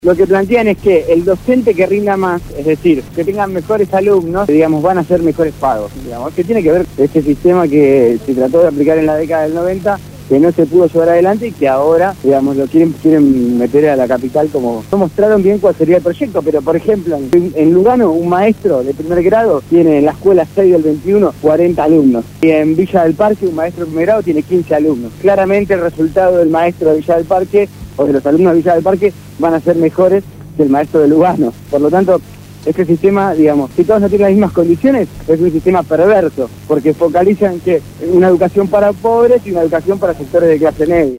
en el programa Desde el Barrio por Radio Gráfica FM 89.3